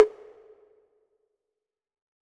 6CONGA HI.wav